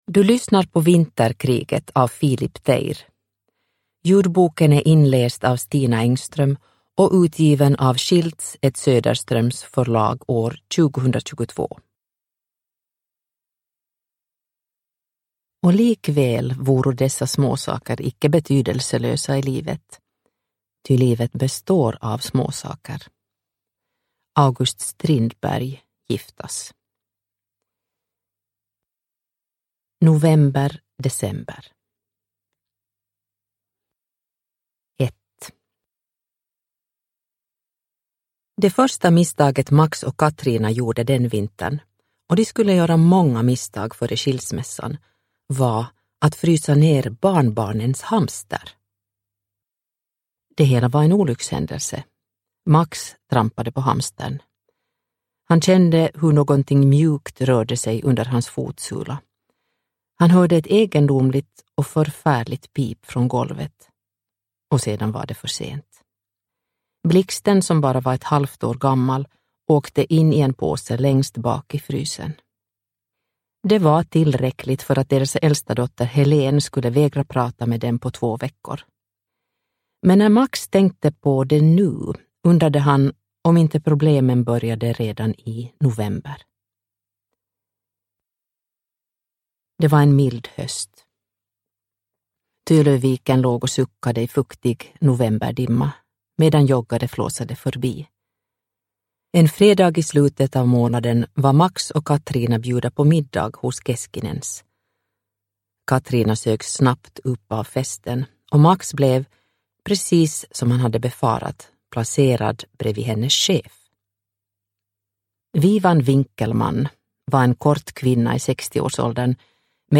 Vinterkriget – Ljudbok